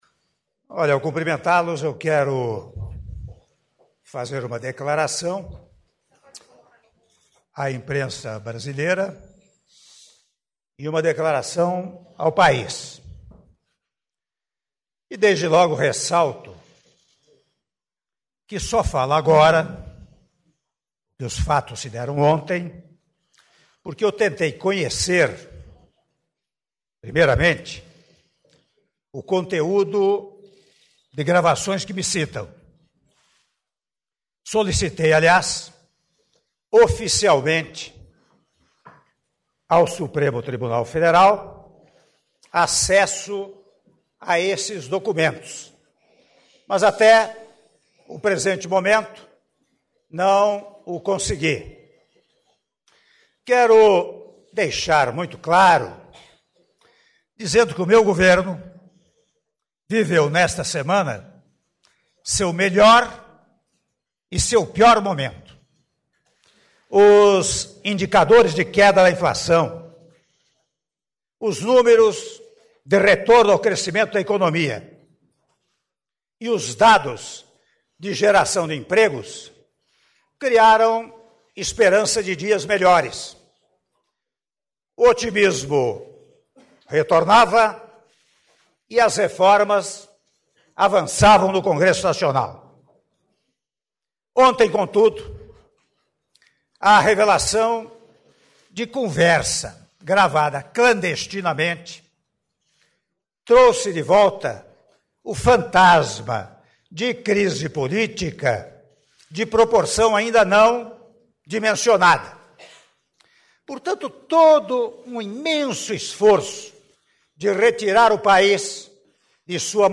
Áudio da declaração à imprensa do Presidente da República, Michel Temer - Brasília/DF (04min43s)